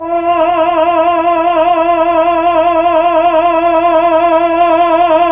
VOX_Chb Ml F_4-L.wav